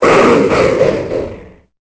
Cri de Draïeul dans Pokémon Épée et Bouclier.